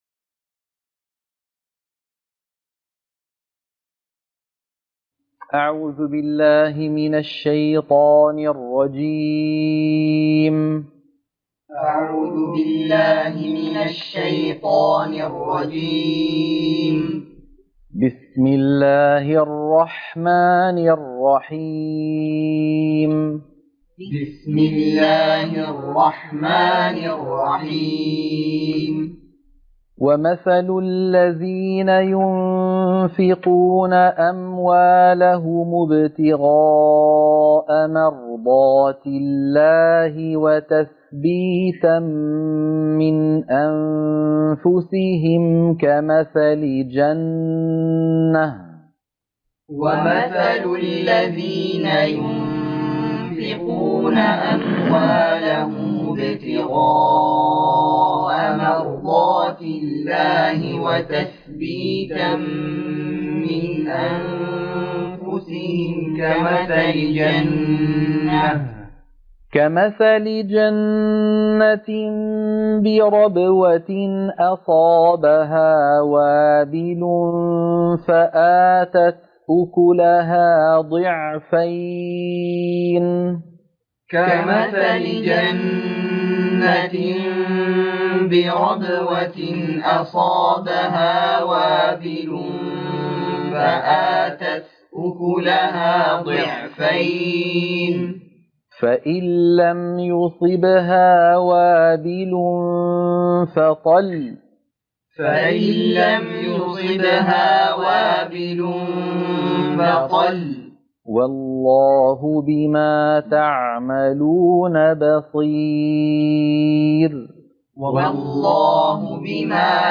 تلقين سورة البقرة - الصفحة 45 التلاوة المنهجية - الشيخ أيمن سويد